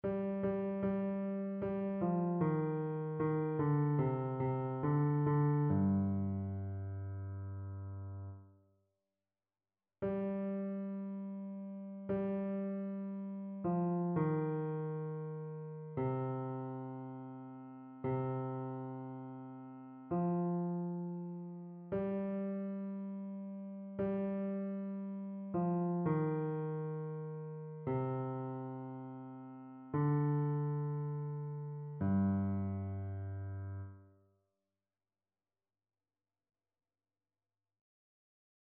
Basse
annee-c-temps-ordinaire-3e-dimanche-psaume-18-basse.mp3